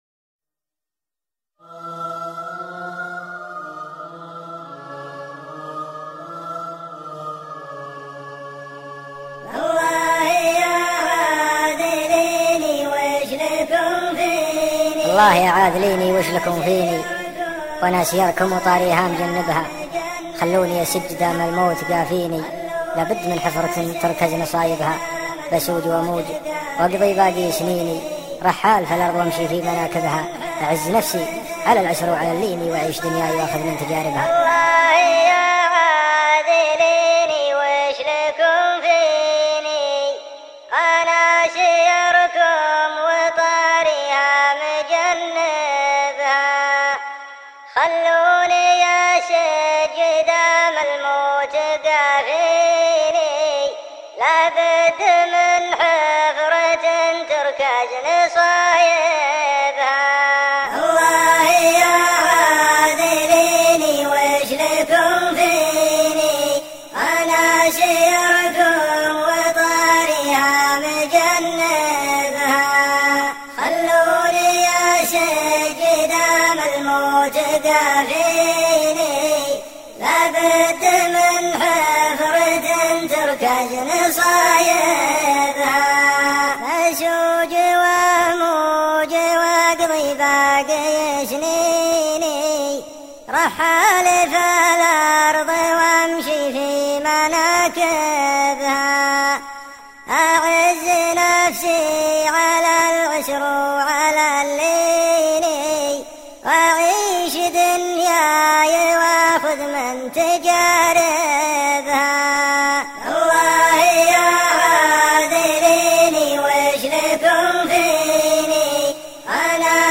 شيلة